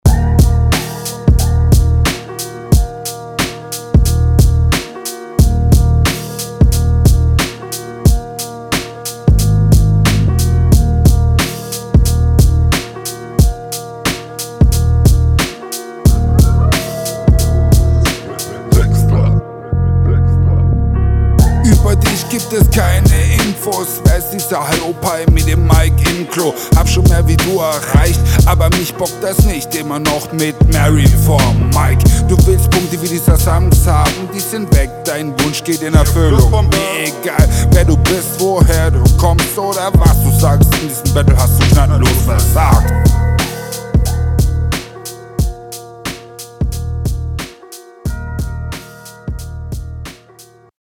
Hier hast du leider etwas undeutliche Doubles …
Sehr kurzes Ding - Rap auf eigenem Territorium wesentlich sicherer und kommt auch ne Ecke …